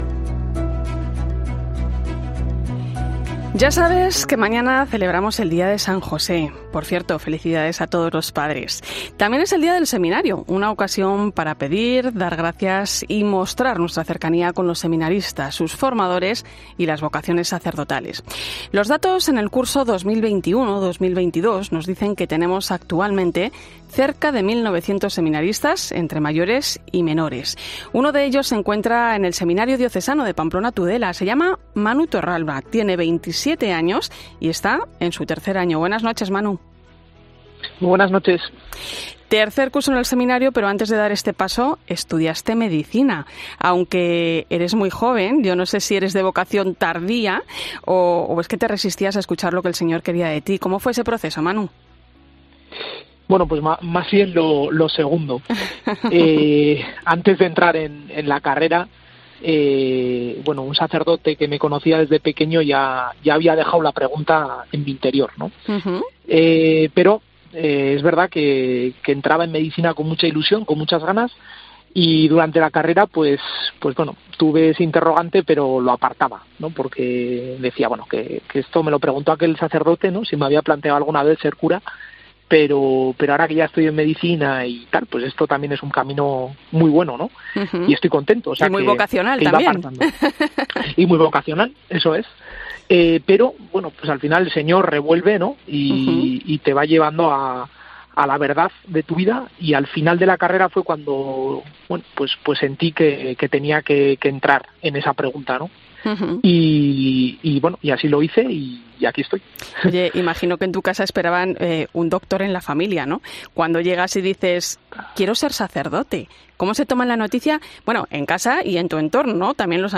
Hablamos con uno de los casi 1900 seminaristas que hay en España con ocasión del día del seminario